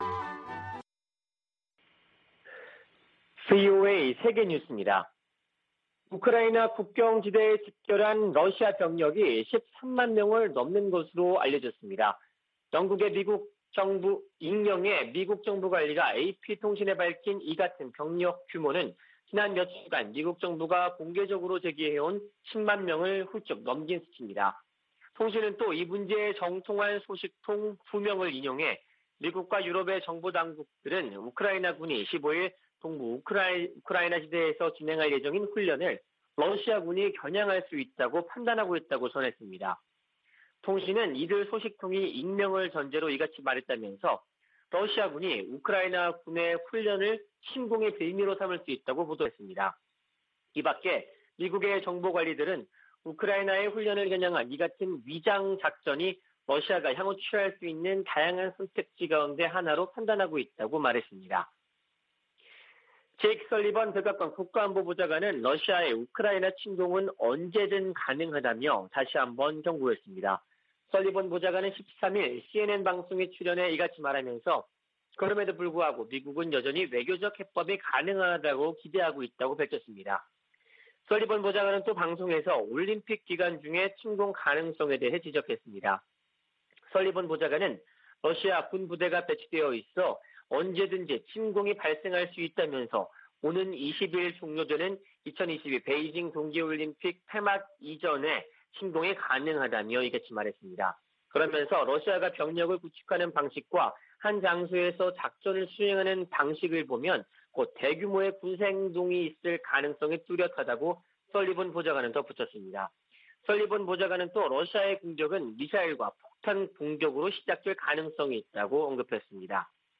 VOA 한국어 아침 뉴스 프로그램 '워싱턴 뉴스 광장' 2021년 2월 15일 방송입니다. 토니 블링컨 미 국무장관은 미-한-일 외교장관 회담에서 북한의 도발에 책임을 물릴 것이라고 언급했습니다. 3국 외교장관들은 공동성명에서 북한의 미사일 도발을 규탄하면서도 외교적 해법을 강조하는 기존 원칙을 확인했습니다. 1년 넘게 공석이던 주한 미국대사에 필립 골드버그 주 콜롬비아 대사가 지명됐습니다.